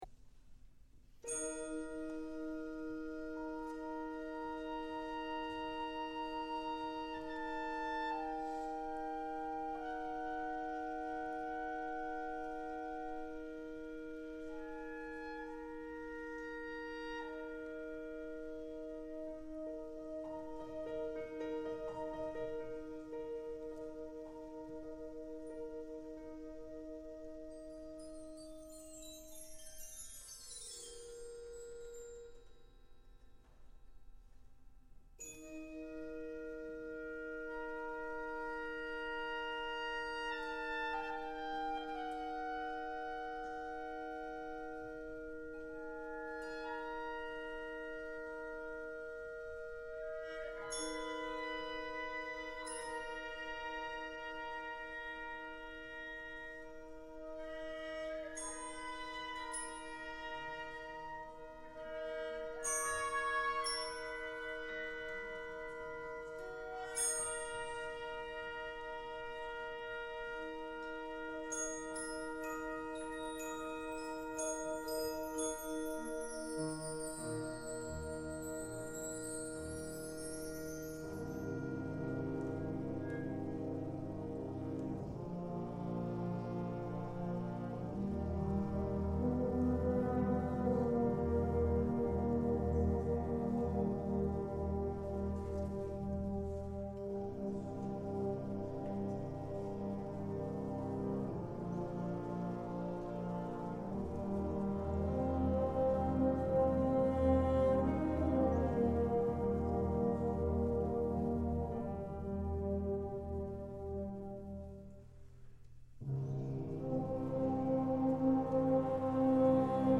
Short Symphony for Wind Ensemble